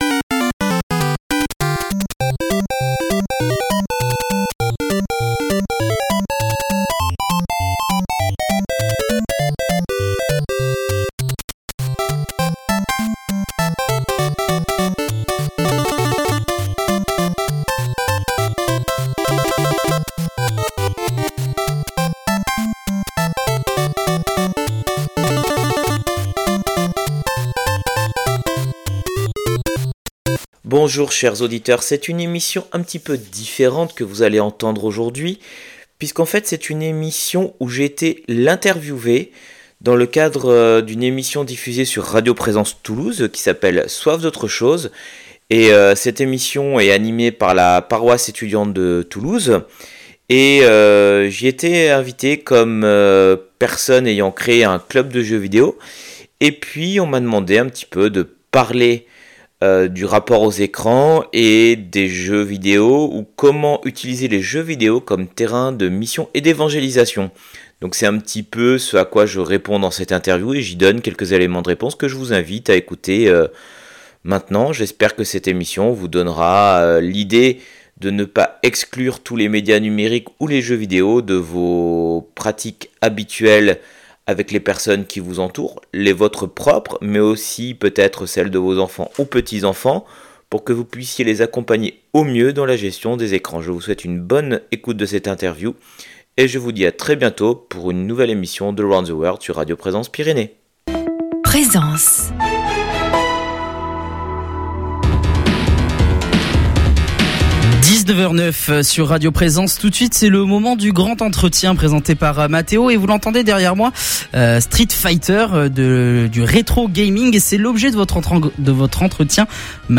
Interview réalisée en direct sur Radio Présence Toulouse dans l'émission Soif d'Autre Chose du 131025 - le grand entretien